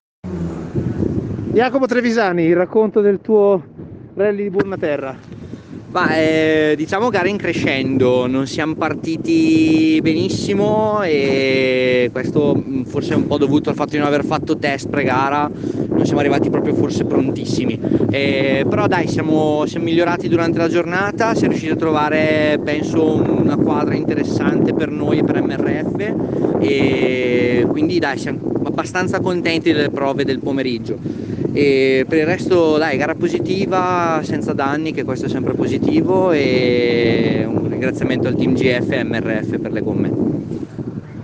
Interviste Rally Liburna Terra 2021
Sabato - Interviste finali